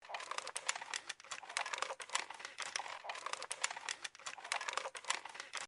Robot Movement Loop
Robot Movement Loop is a free ambient sound effect available for download in MP3 format.
Robot Movement Loop.mp3